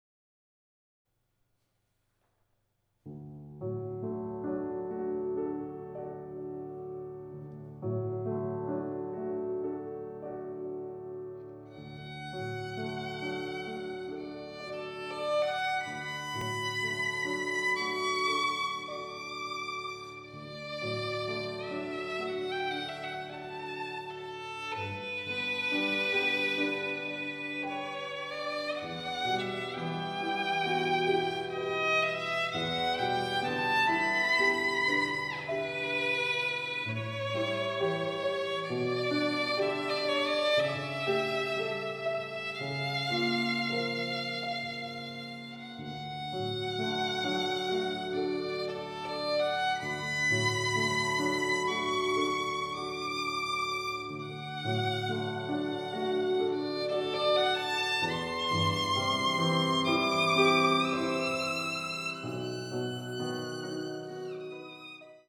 ヴィオラ